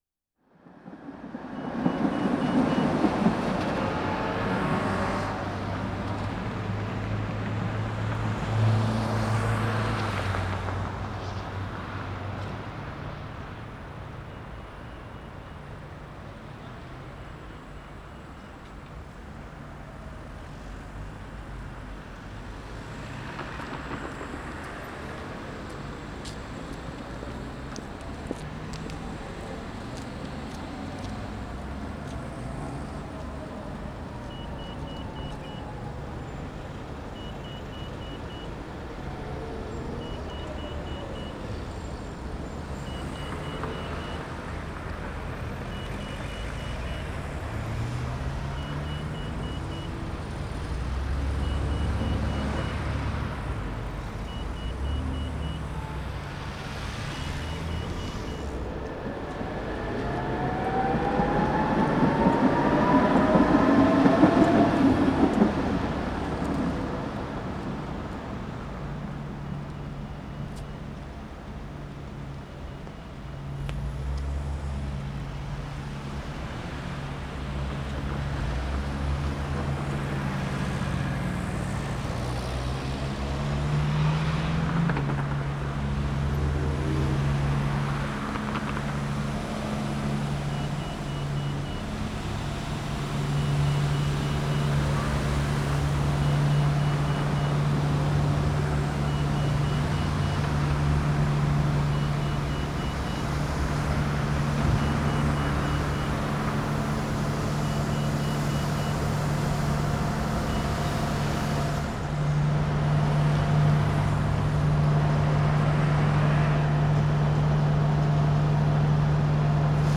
Crosswalk signals (usual crosswalk signal at several crossroads in Berlin) 3:42